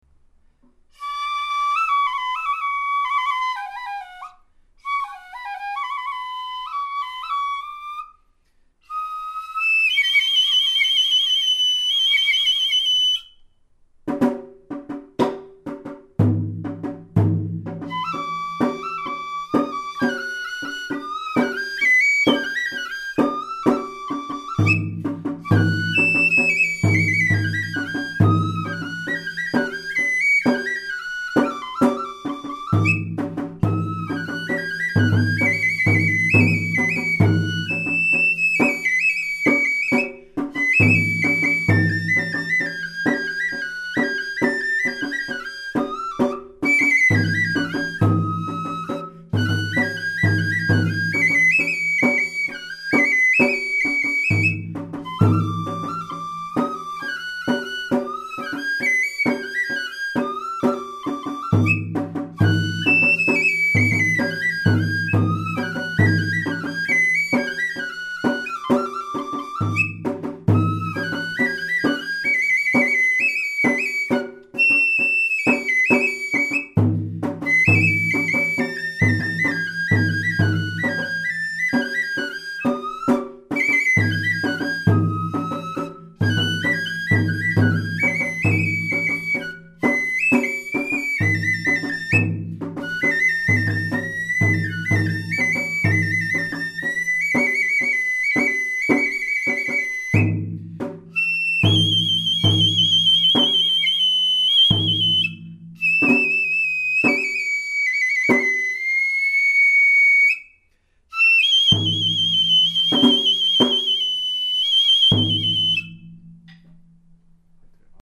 なお、笛の方はとっても上手ですが、太鼓（実は大部分が私）は数箇所間違っております。
吹き出しの高音や太鼓の入りは、知立でよく使われているパターンでやっています。